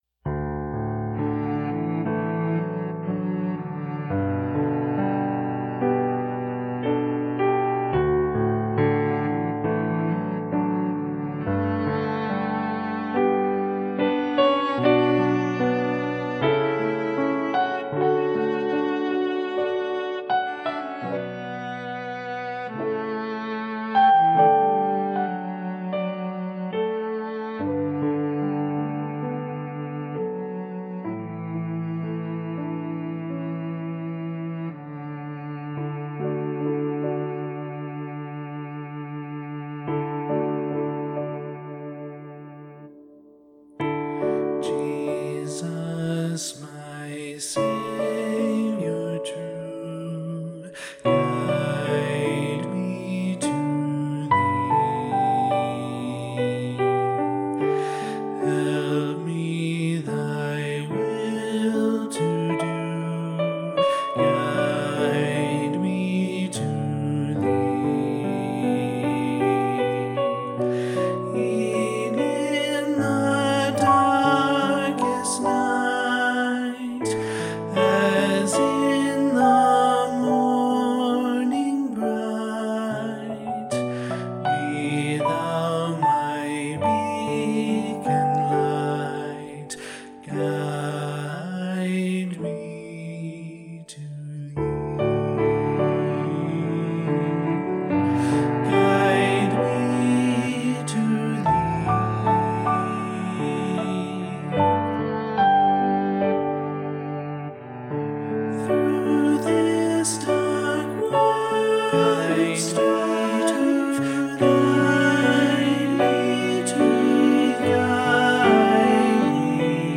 Voicing/Instrumentation: SATB
Cello Optional Obbligato/Cello Accompaniment